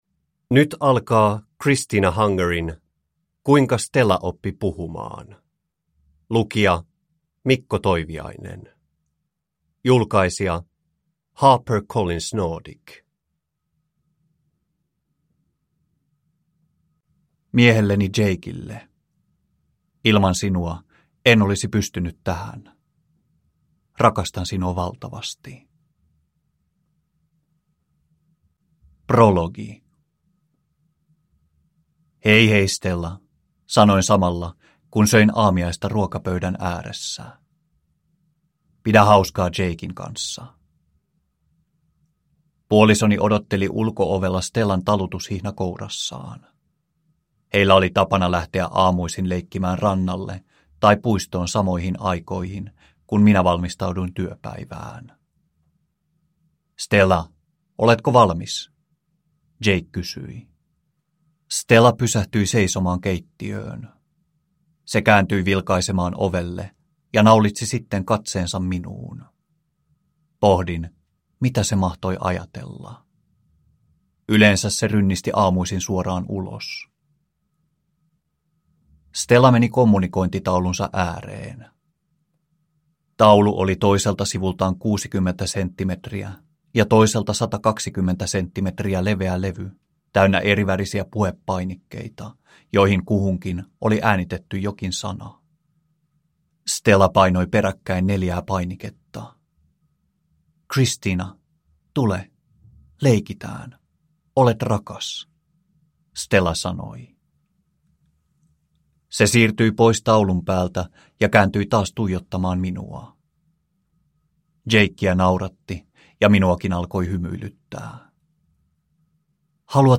Kuinka Stella oppi puhumaan – Ljudbok – Laddas ner